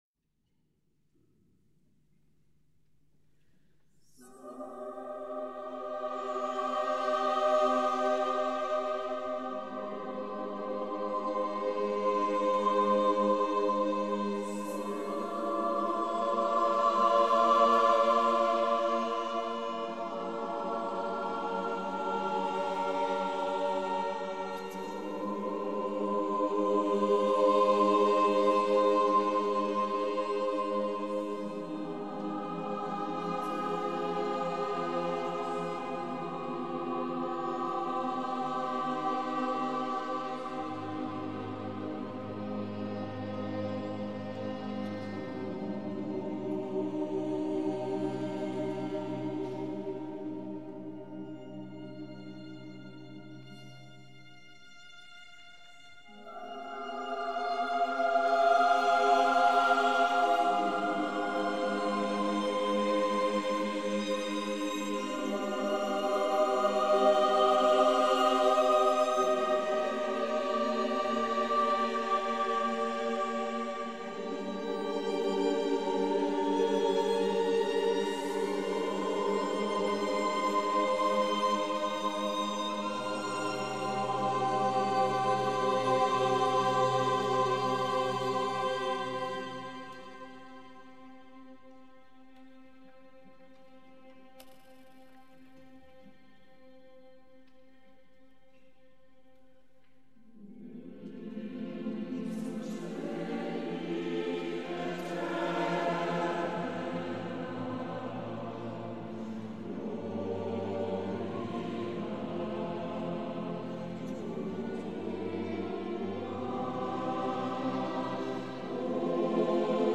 St. Jodokus - Immenstaad - 31. März 2012